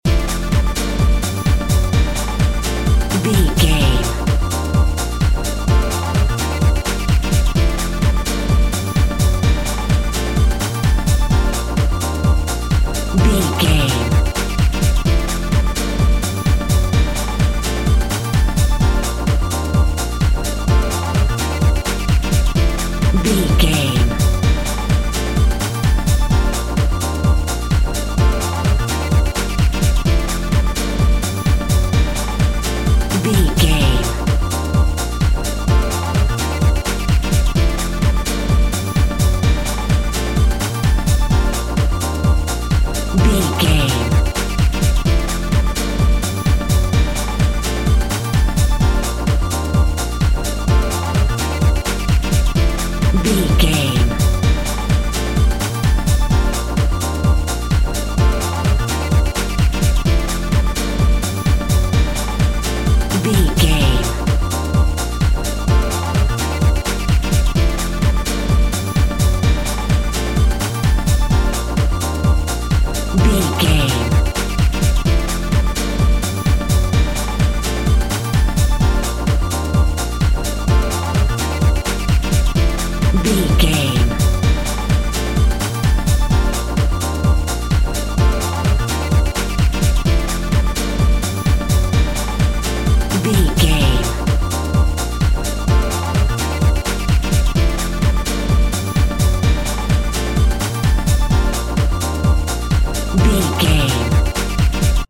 Ionian/Major
B♭
Fast
driving
energetic
groovy
drum machine
electronic
techno
synth leads
synth bass